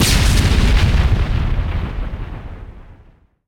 grenadeexplode.ogg